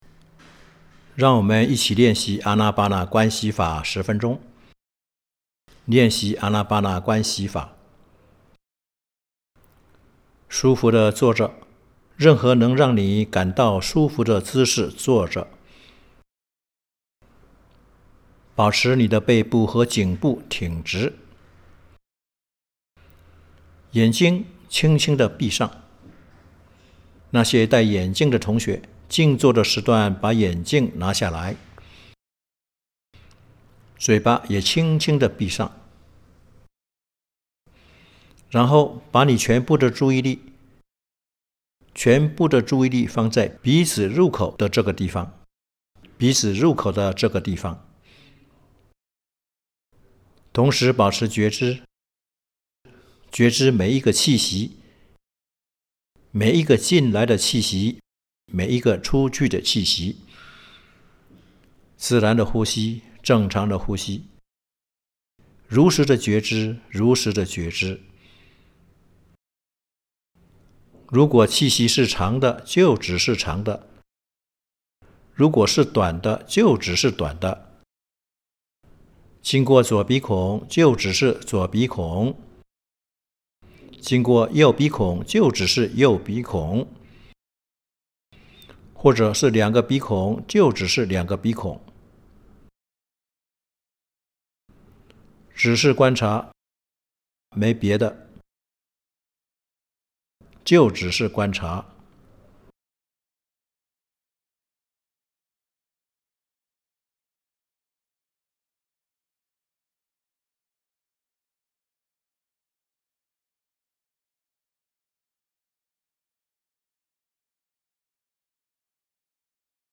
Group Sitting
Dhamma Giri